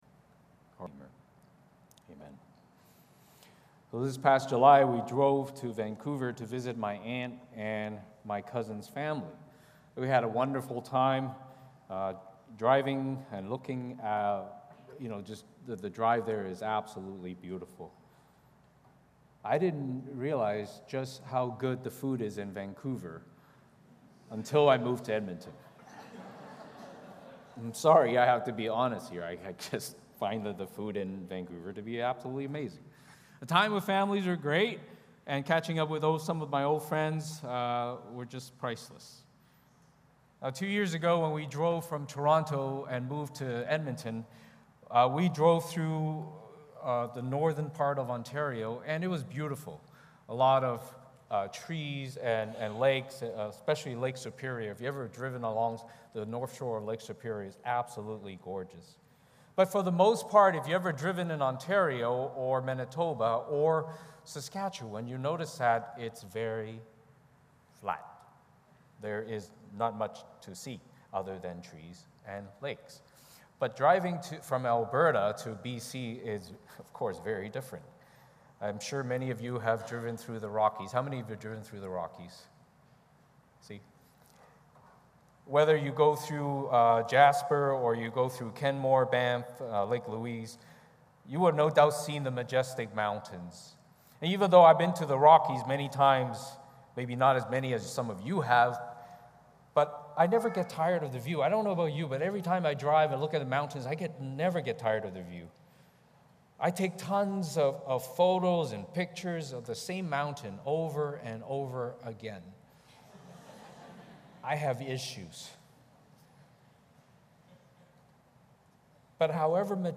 Psalm 19 Service Type: Sunday Morning Service Passage